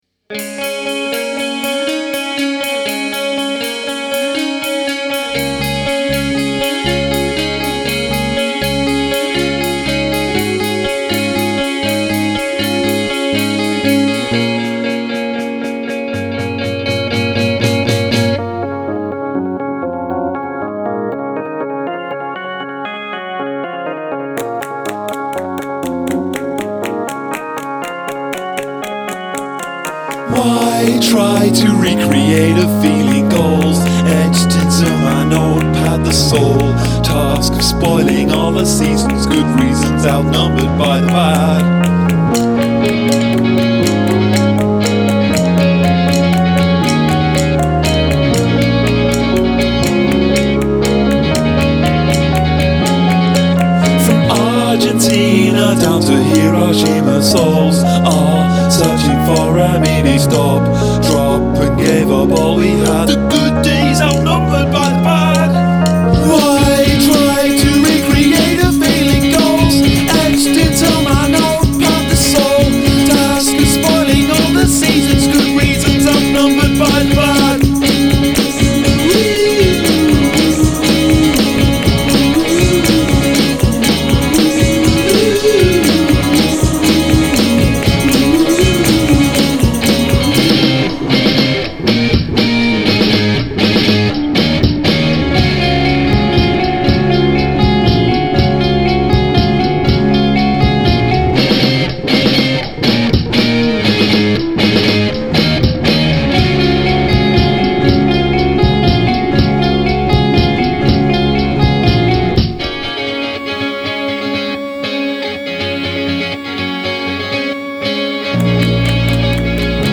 Odd Time Signature